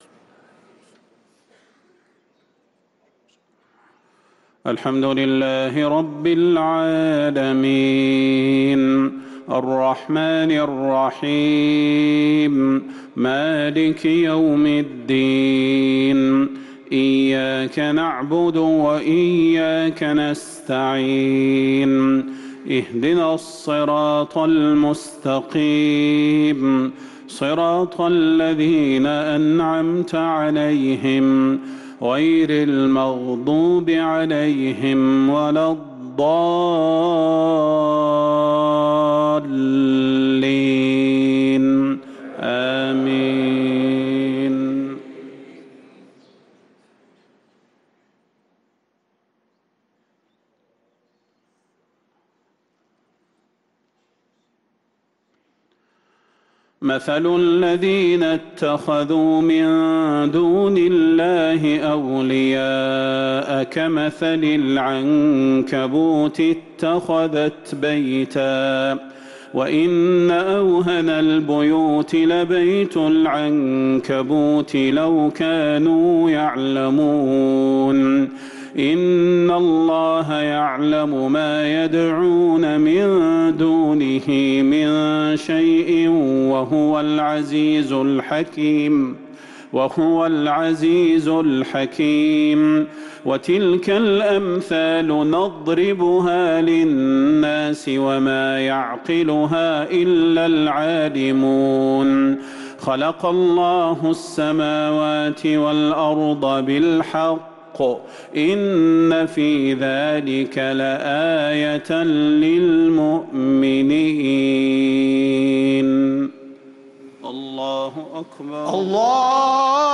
صلاة المغرب للقارئ صلاح البدير 27 ربيع الآخر 1445 هـ
تِلَاوَات الْحَرَمَيْن .